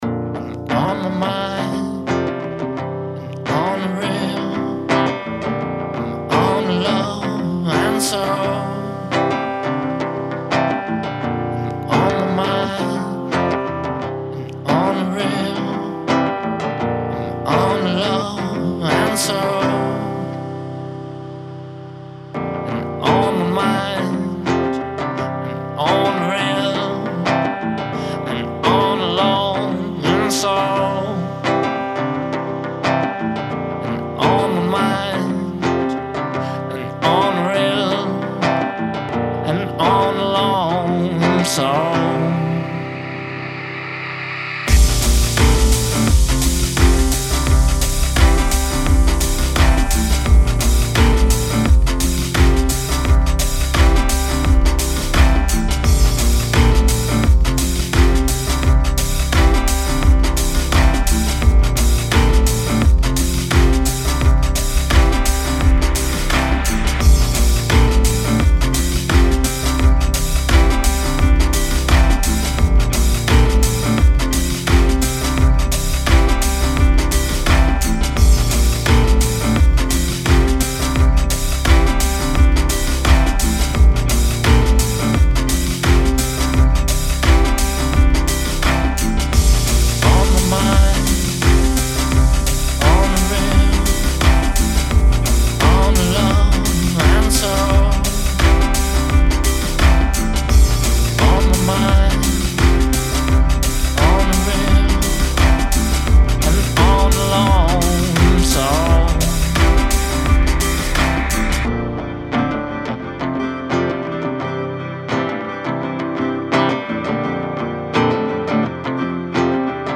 Продолжаю пытаться отдать своё творчество в хорошие руки) На сей раз попсовенький звук) С стилем и жанром я не определился. Вообщем как всегда есть весь проджект для дальнейшего использования) P.S Сейчас я перебираю архивы и пересвожу свои композиции (так как за 5 лет у меня многое поменялось ) Мониторов нет, и я не могу нормально контролировать звук.